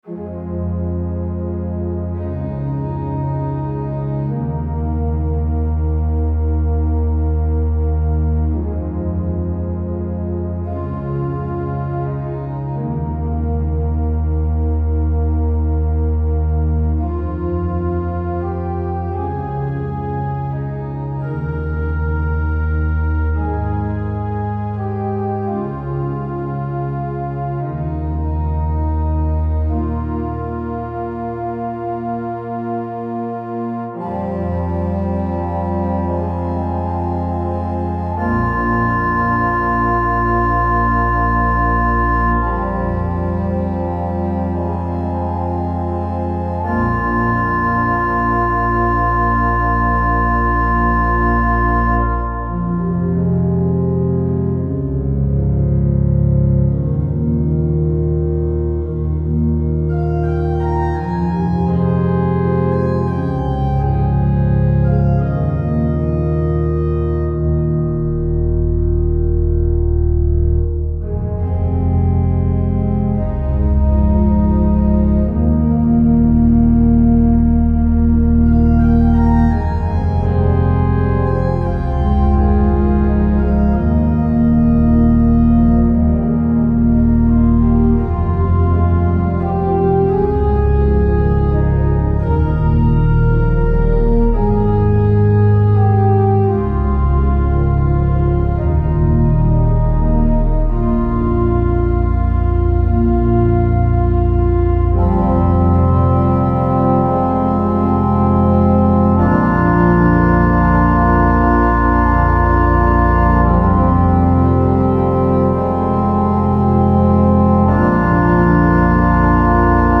pipe organ